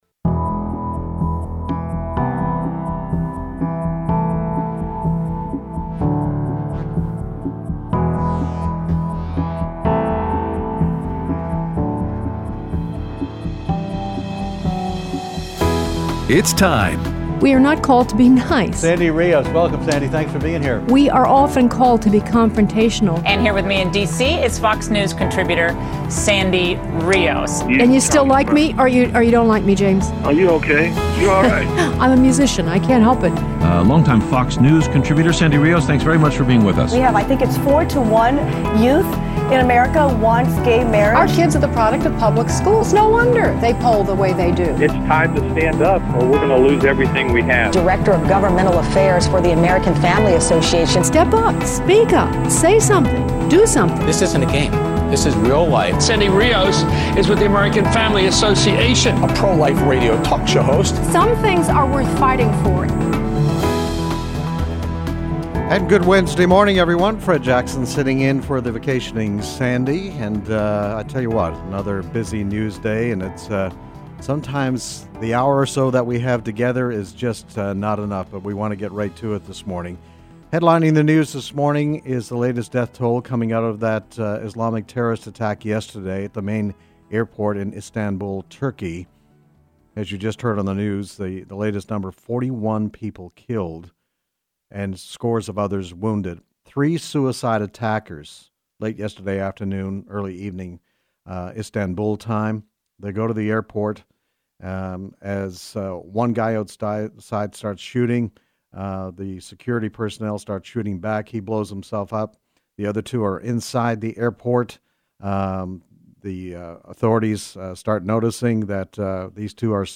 Guest Host
interviews